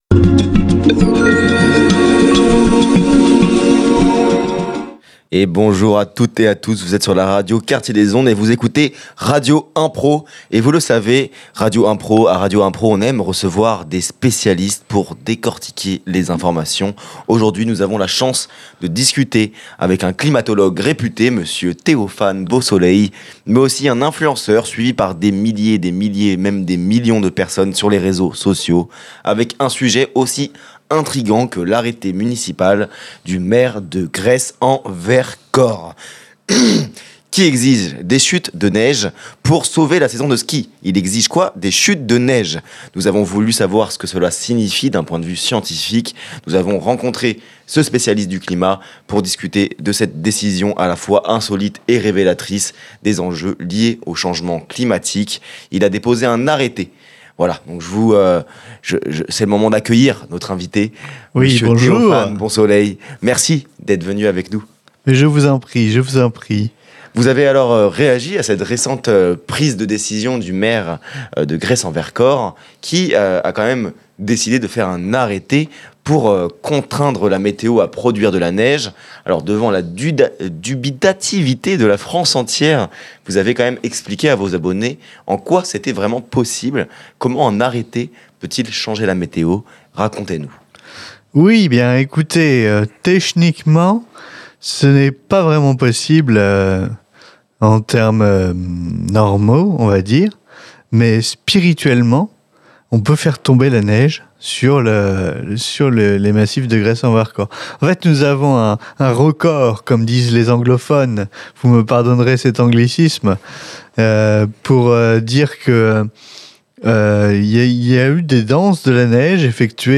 Tout cela crée des interviews tantôt humoristiques, tantôt poétiques, tantôt engagées et parfois un peu de tout ça !